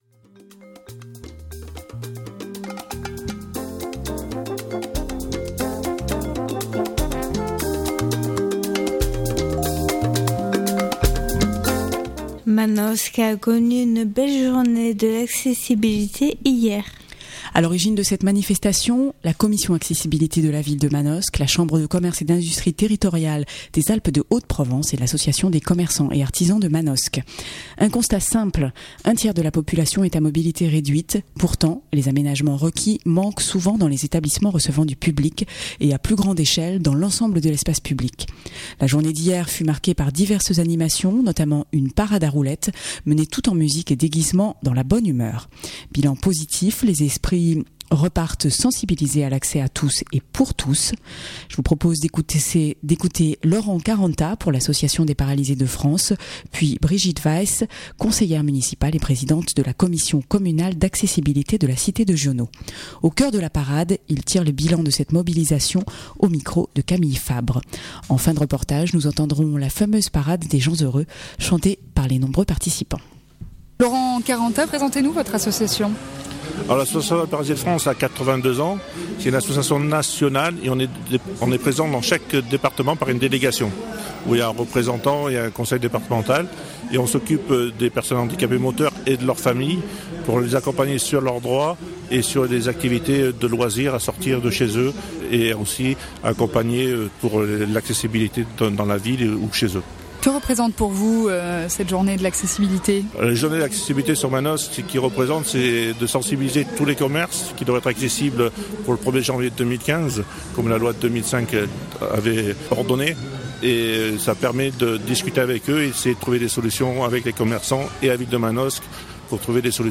En fin de reportage, nous entendrons la fameuse « Parade des gens heureux » chantée par les nombreux participants.